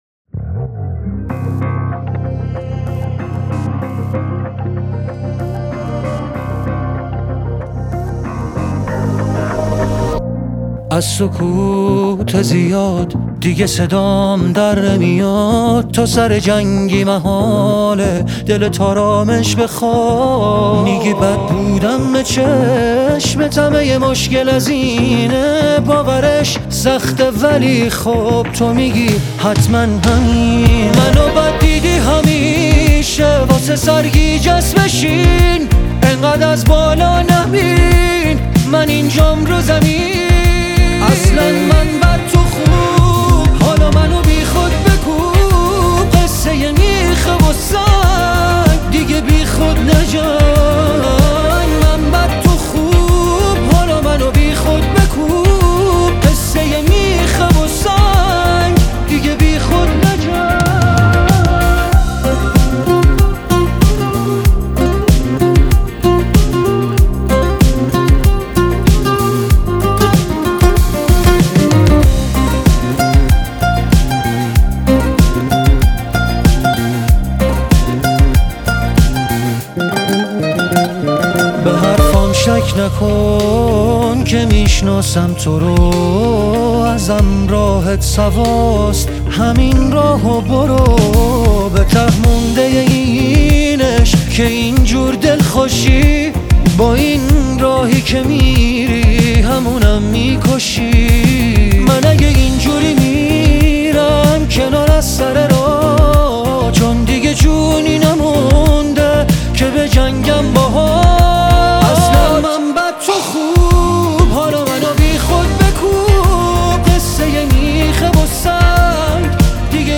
اهنگ عاشقانه